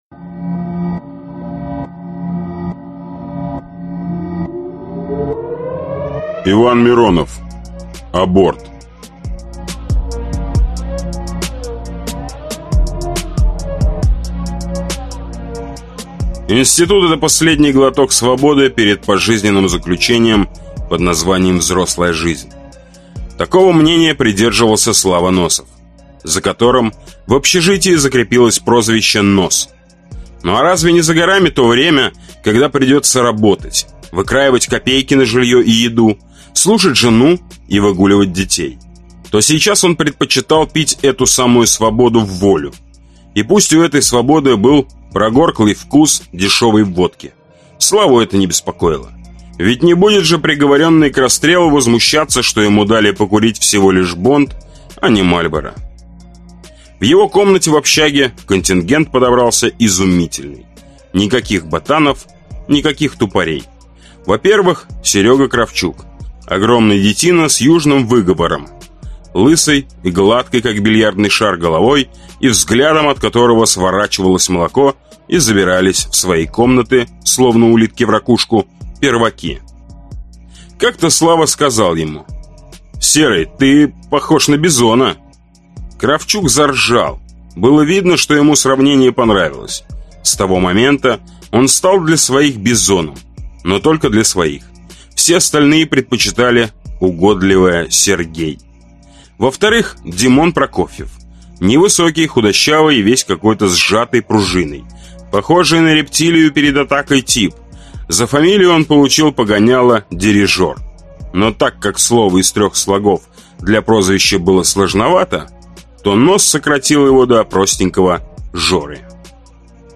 Аудиокнига Потеряшка | Библиотека аудиокниг